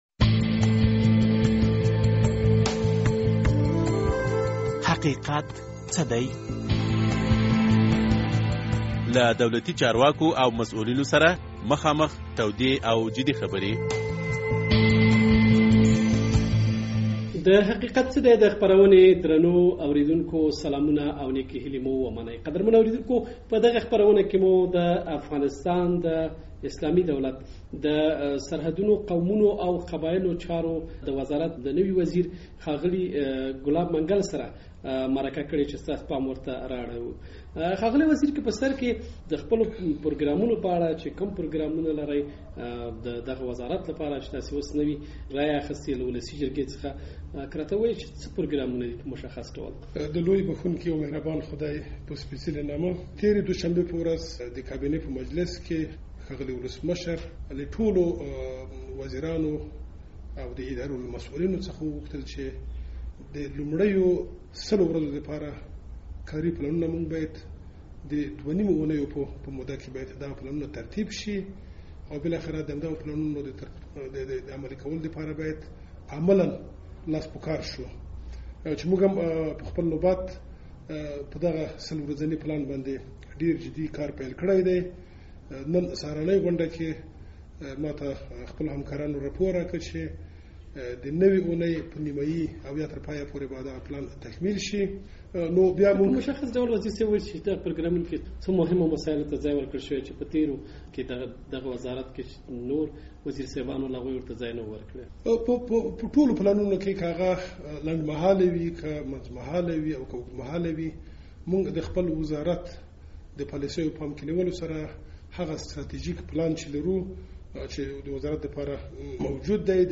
د حقیقت څه دی په دې خپرونه کې مو د افغانستان د سرحدونو، قومونو او قبایلو چارو وزارت د نوي وزیر ګلاب منګل سره مرکه کړې ده.